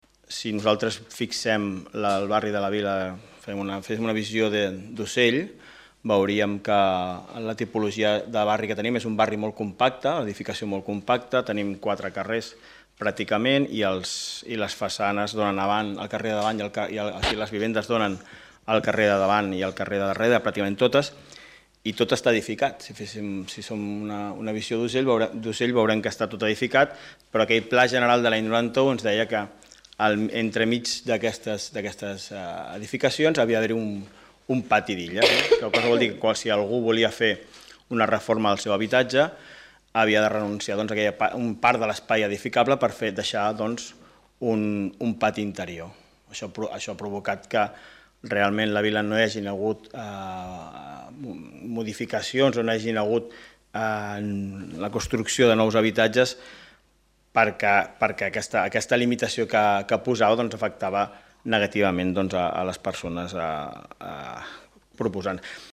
L’Ajuntament de Martorell va aprovar, en el Ple Ordinari d’ahir a la nit, una modificació puntual del Pla General d’Ordenació Urbana (PGOU) de Martorell de gran rellevància per La Vila.
Albert Fernández, regidor de Planificació Urbanística